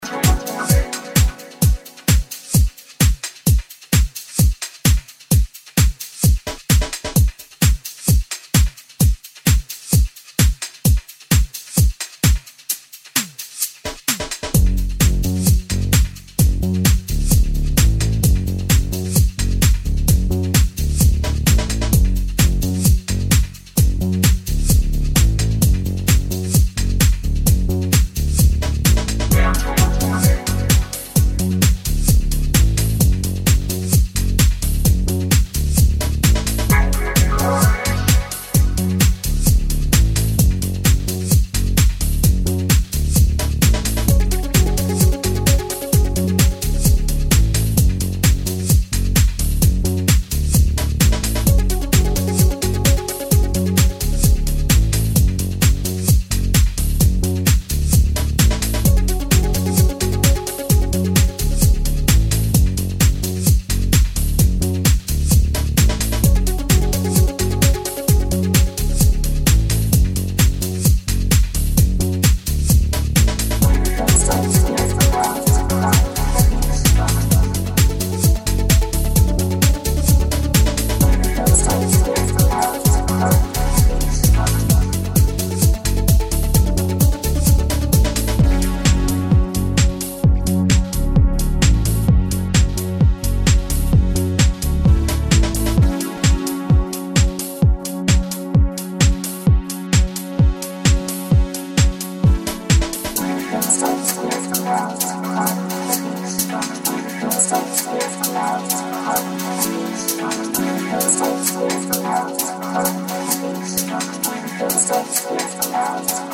a splash of fun in a house key